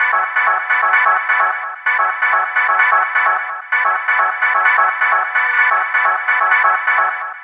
Lead 129-BPM 1-F#.wav